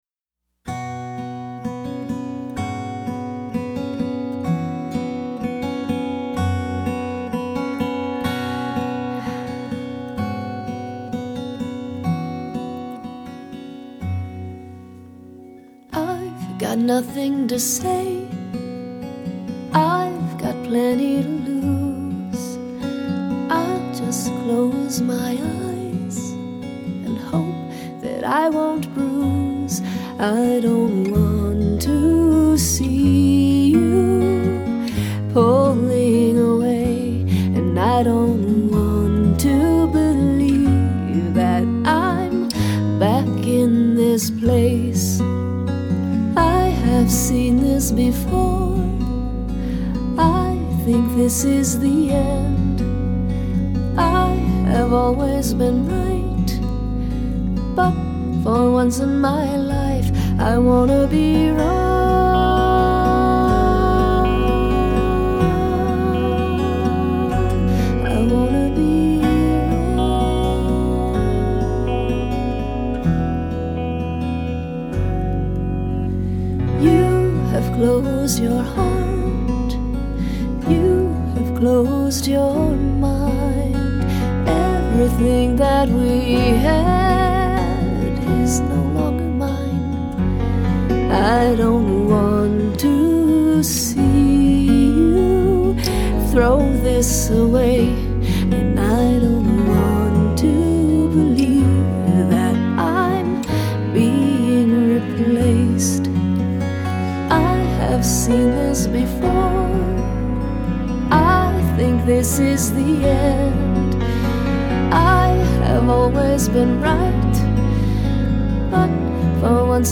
Ballad Fmvx, Full band